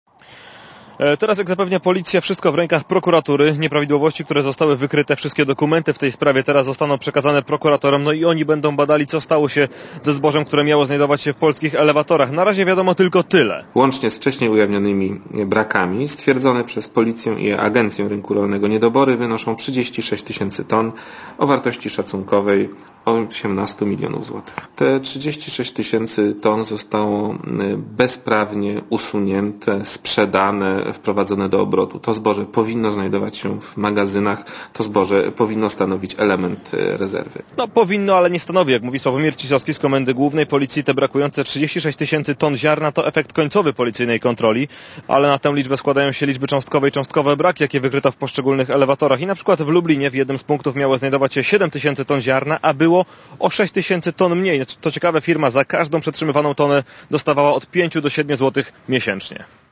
(RadioZet) Źródło: (RadioZet) Relacja reportera Radia Zet